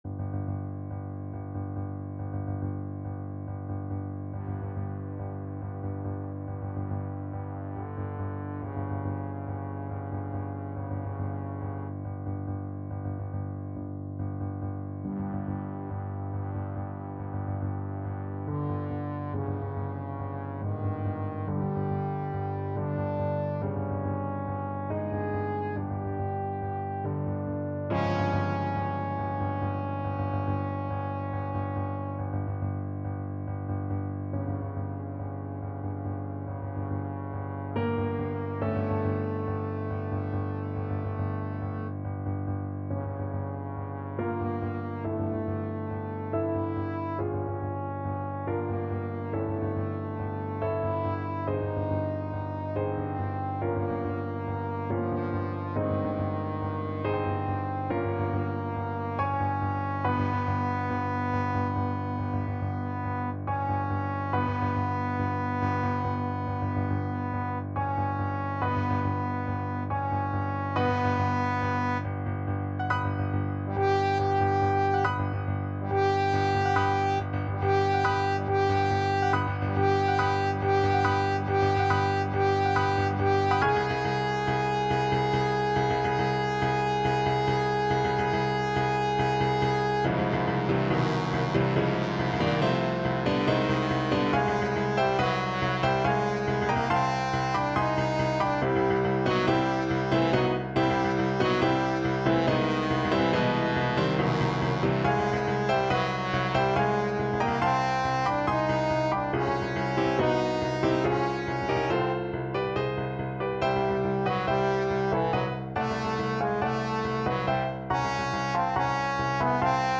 Trombone version
5/4 (View more 5/4 Music)
Allegro = 140 (View more music marked Allegro)
Classical (View more Classical Trombone Music)